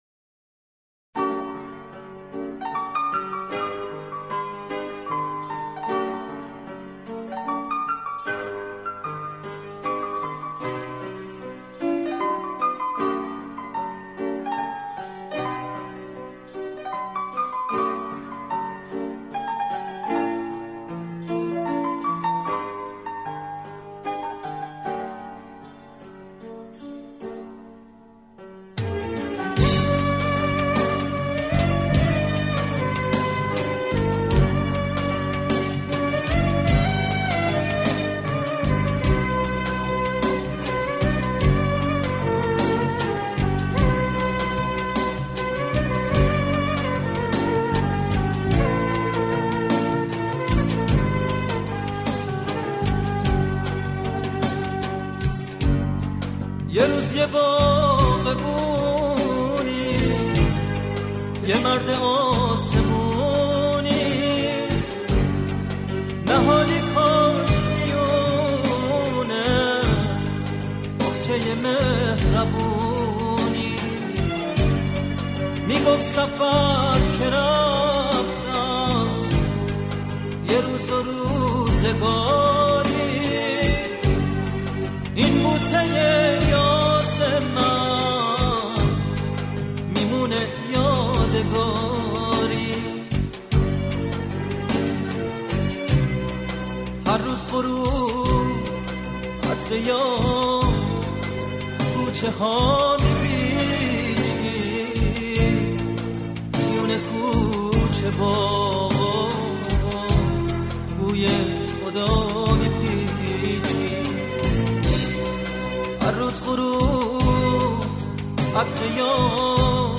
** موسيقي پاپ و فولكلور **